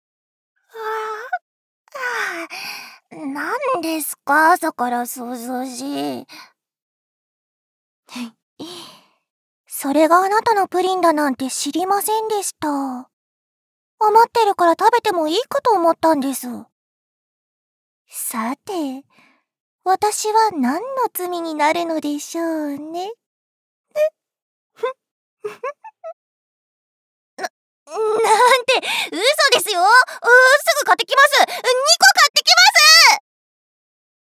通常版 演技版１ 演技版２
Voice Sample